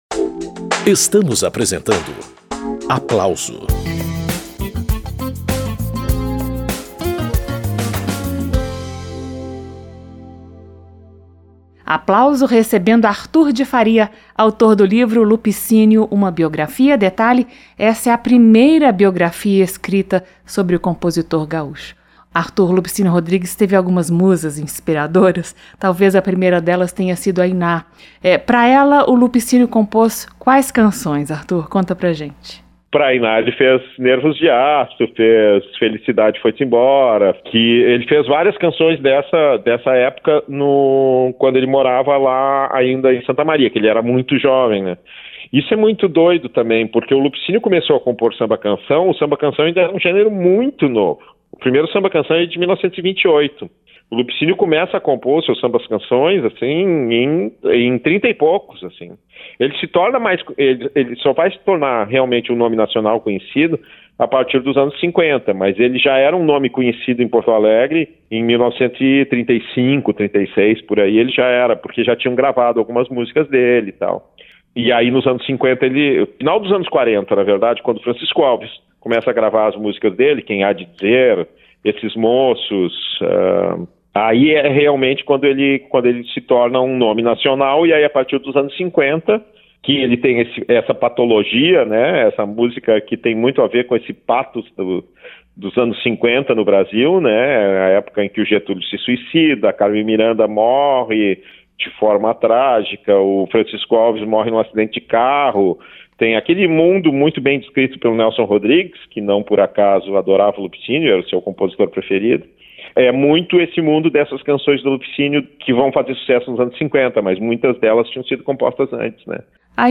Relembre entrevista sobre os amores e desamores de Lupicínio Rodrigues - Rádio Câmara